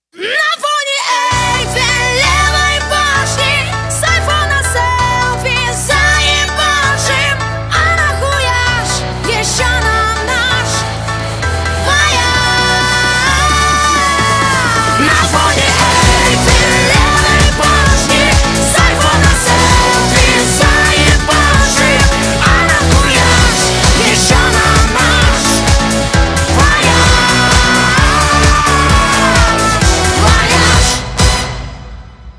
Рок Металл
громкие